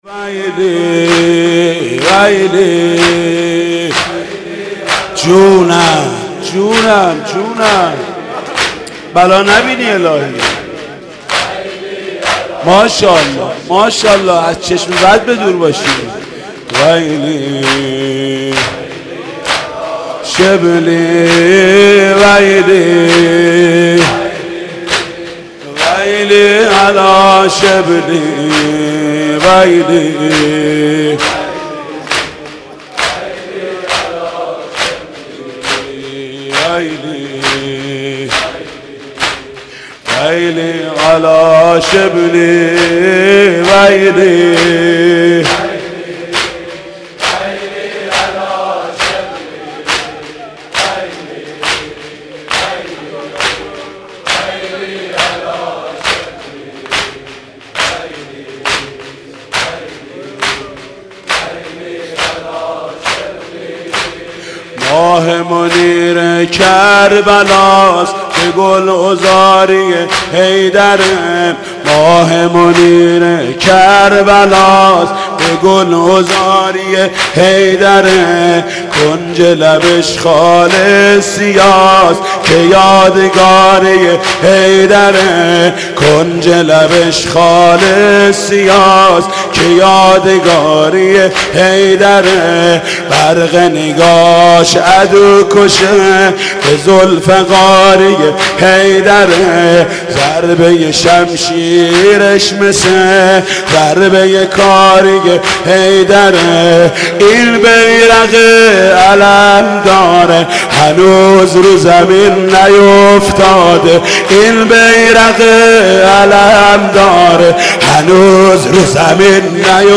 گل کریستیانو رونالدو به الفیحا روی پاس زیبای کومان / فیلم برچسب‌ها: شب تاسوعای حسینی شب نهم محرم حاج محمود کریمی علمدار کربلا دیدگاه‌ها (5 دیدگاه) برای ارسال دیدگاه وارد شوید.